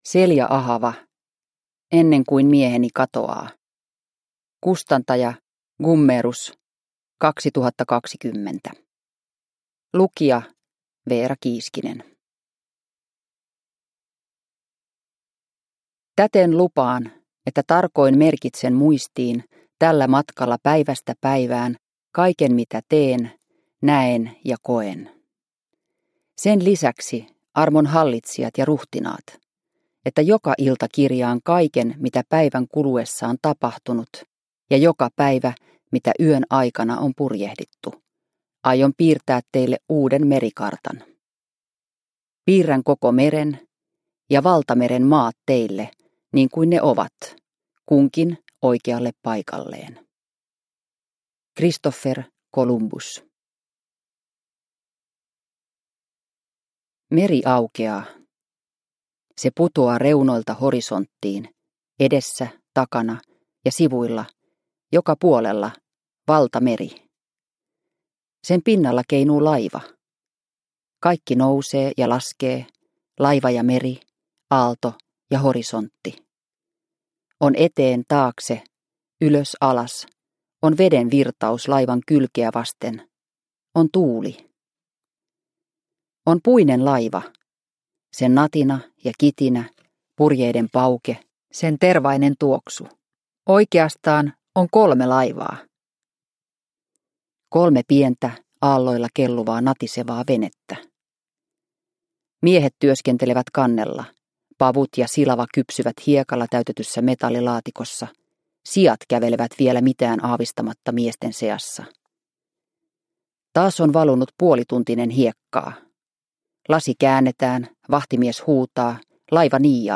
Ennen kuin mieheni katoaa – Ljudbok – Laddas ner